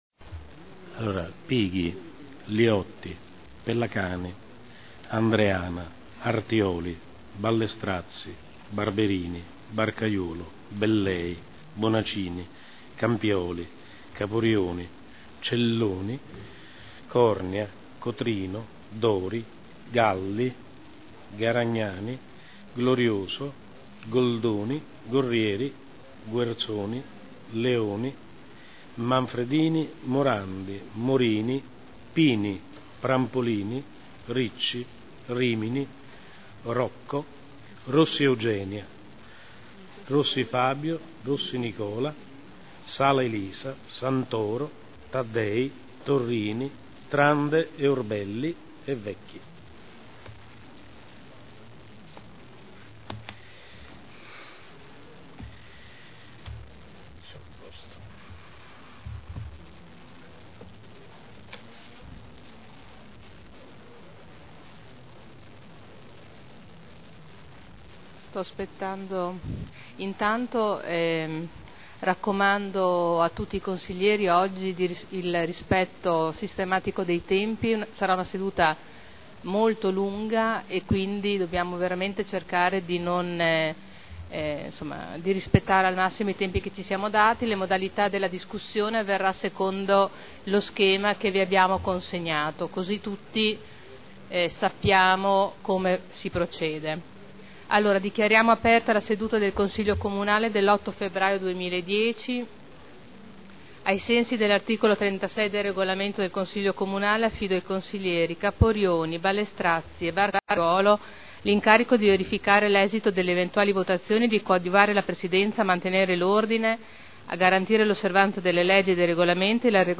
Appello e apertura del Consiglio Comunale